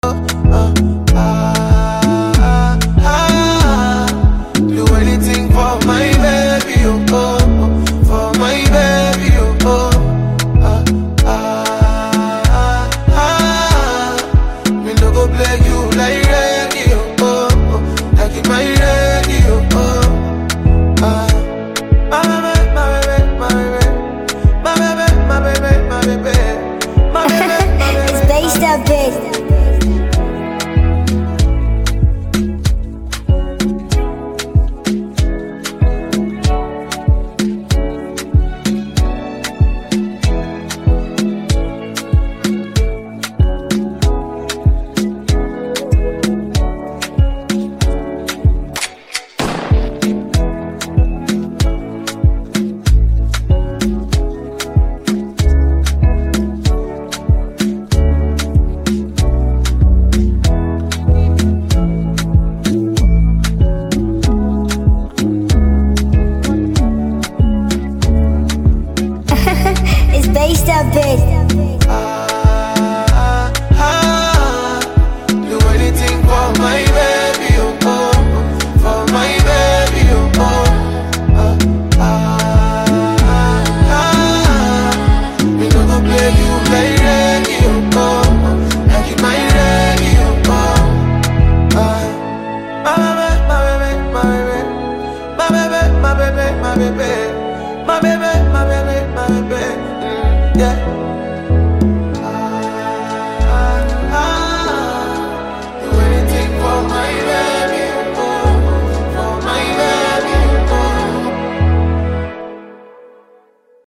Download instrumental + hook below…